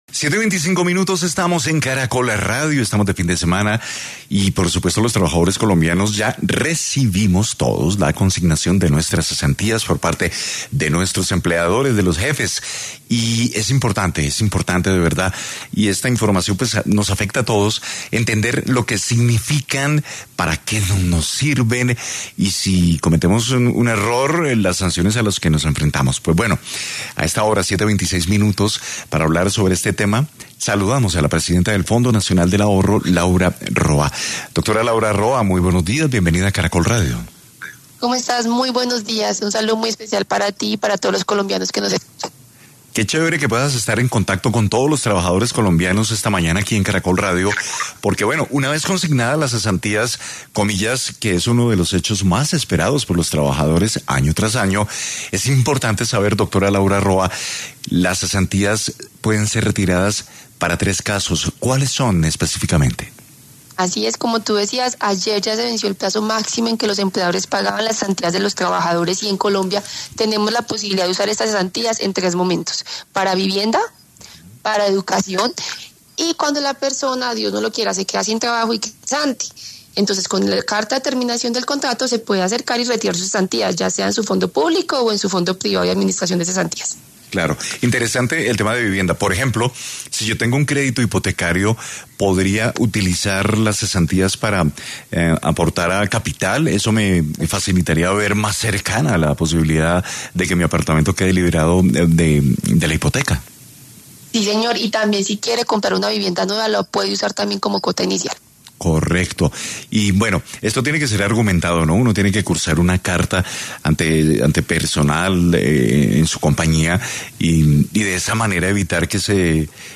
En diálogo con Caracol Radio, la presidenta del Fondo Nacional del Ahorro, Laura Roa, explicó los casos en los que las personas pueden hacer el retiro de este dinero que corresponde a una prestación social.